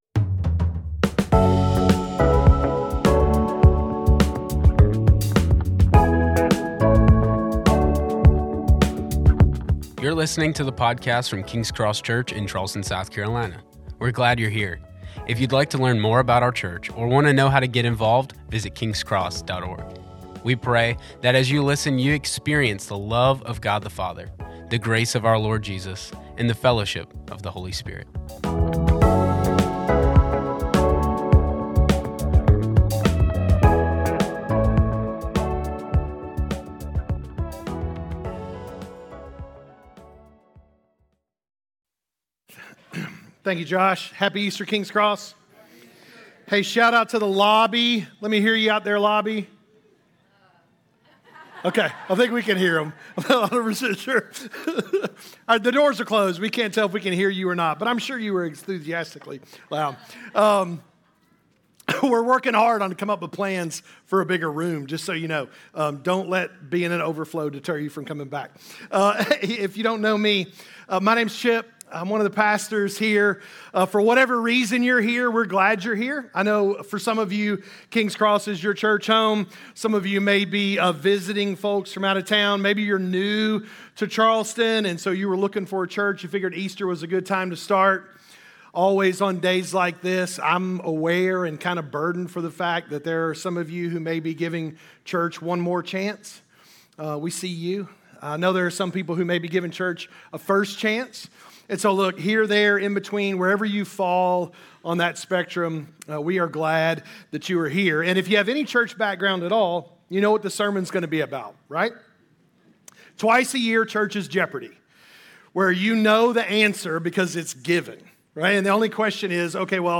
A message from the series "King Jesus."